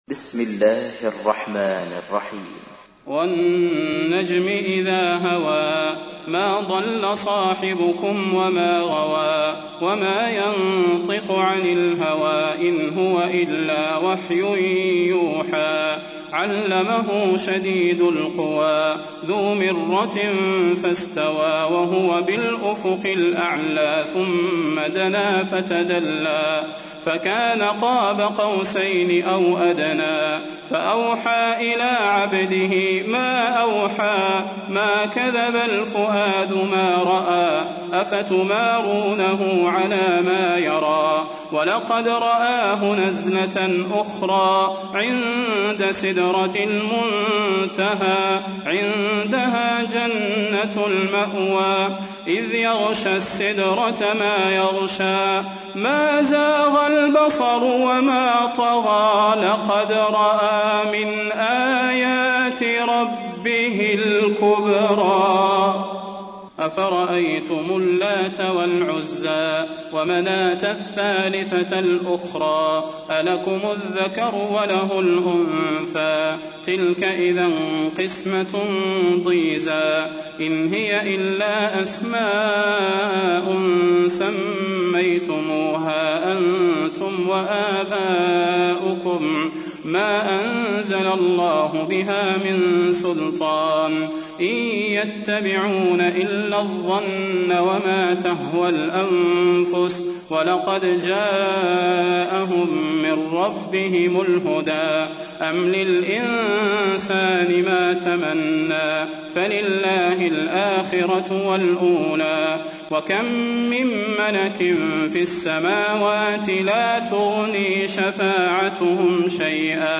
تحميل : 53. سورة النجم / القارئ صلاح البدير / القرآن الكريم / موقع يا حسين